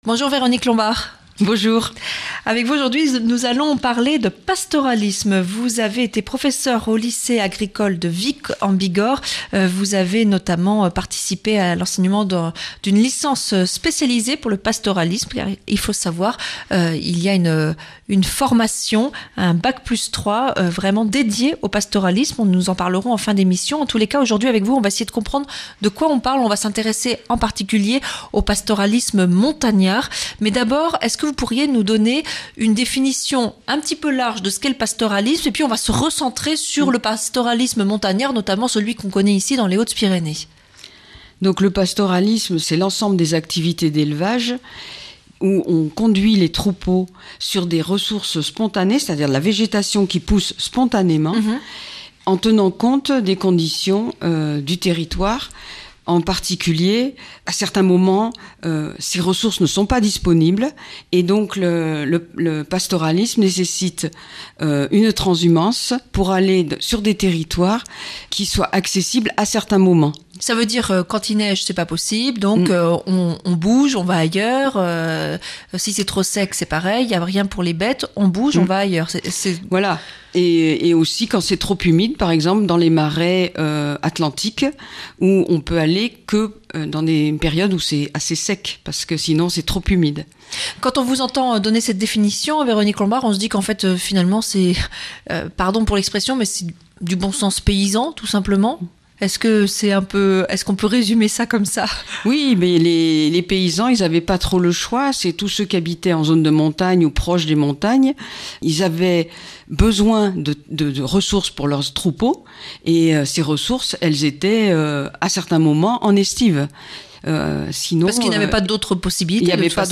Interview et reportage \ Le pastoralisme